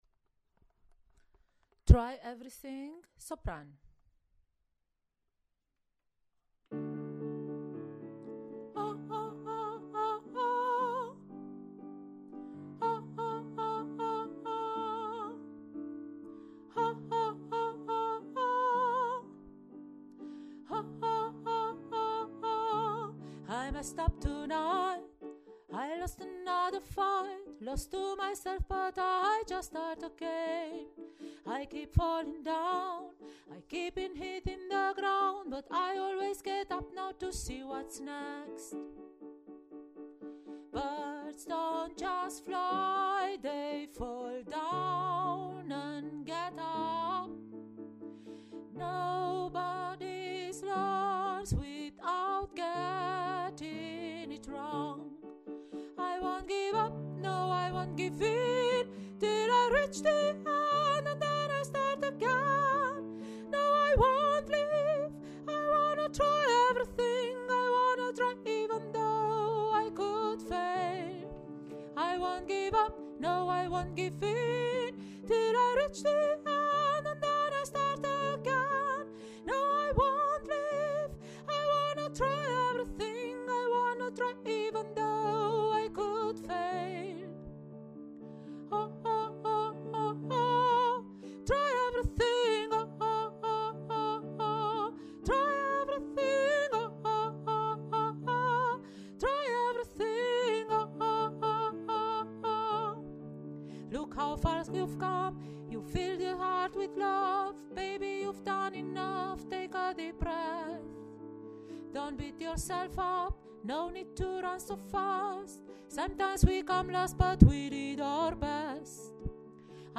Try-everything-Sopran.mp3